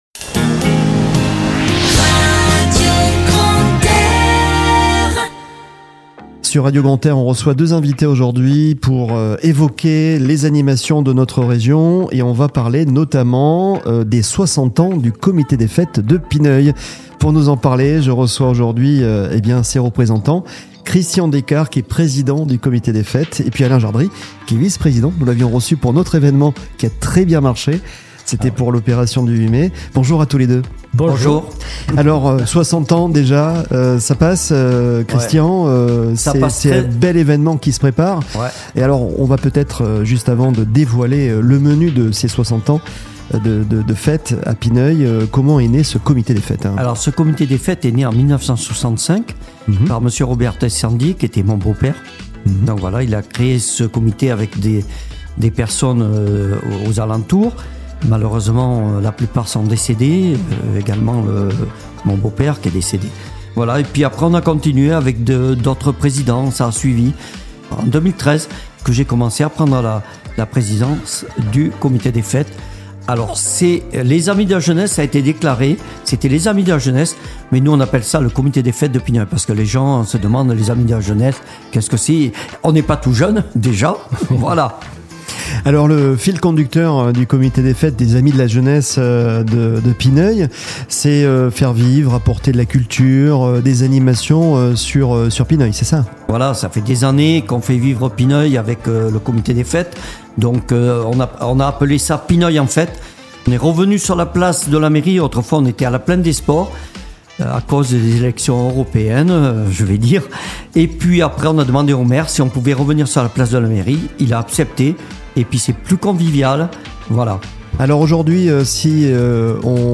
Les invités sur Radio Grand "R"